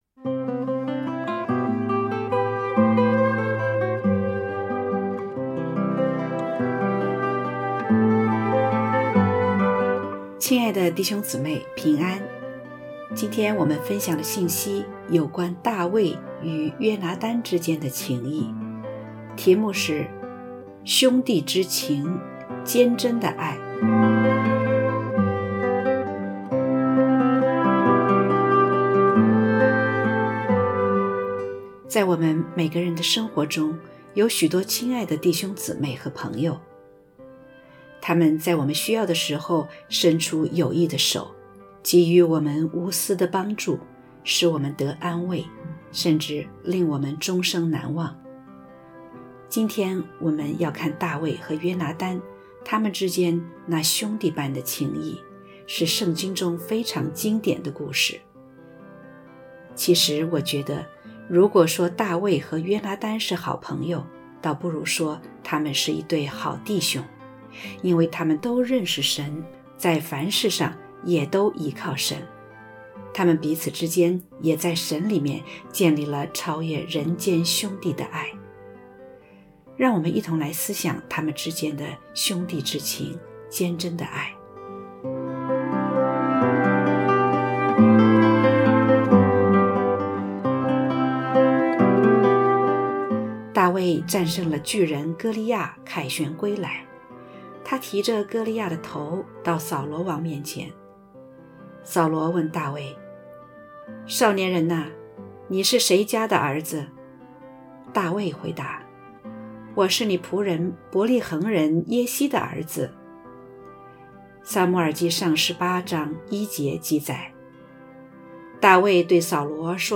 （合成）兄弟之情，坚贞的爱.mp3